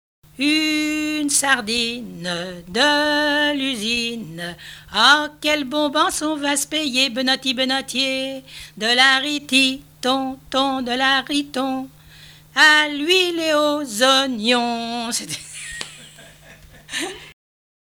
Mémoires et Patrimoines vivants - RaddO est une base de données d'archives iconographiques et sonores.
chanson chantée pour aller en pique-nique en forêt
Pièce musicale inédite